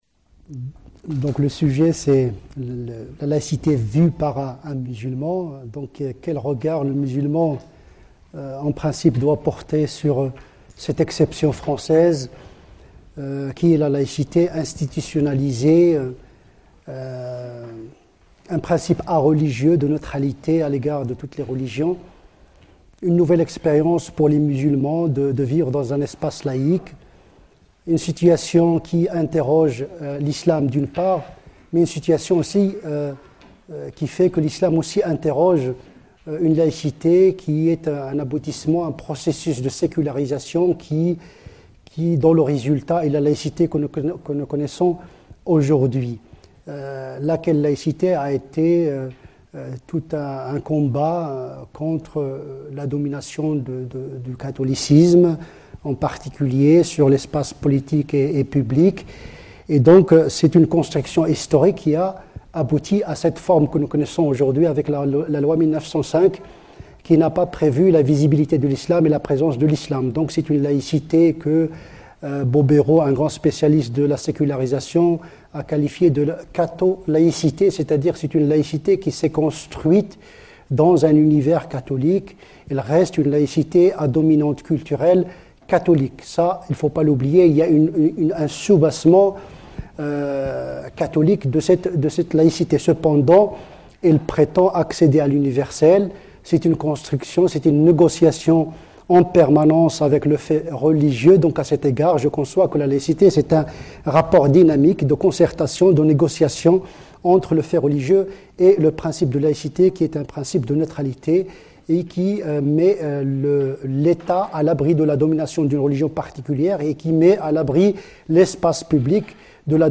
Mais dans le contexte actuel de réémergence du fait religieux et des revendications identitaires, comment négocier la pratique musulmane en France? La conférence a été donnée à l'Université Victor Segalen Bordeaux 2 dans le cadre du cycle de conférences "L'invité du Mercredi" / Saison 2003-2004 sur le thème "Demain".